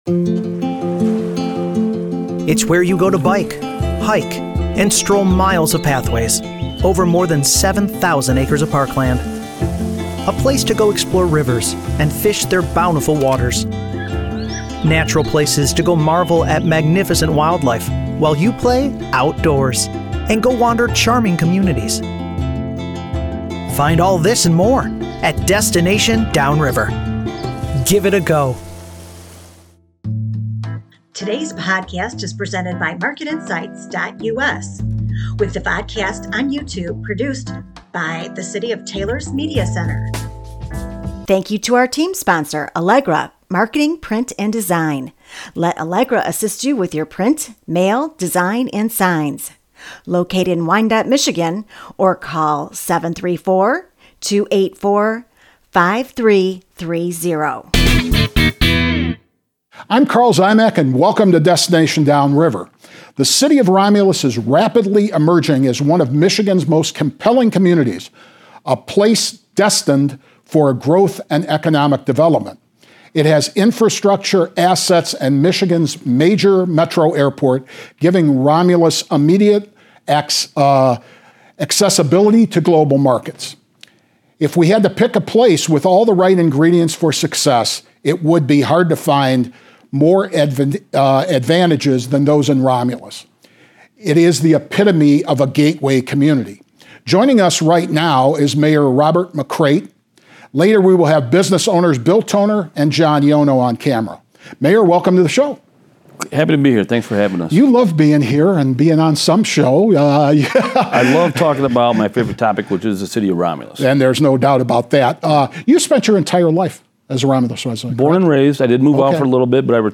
The Destination Downriver Podcast, presented by Market Insights and produced by the City of Taylor provides awareness of the Downriver community through interviews with local businesses owners and community leaders. The Destination Downriver Podcast serves as an additional source of information for citizens of Downriver.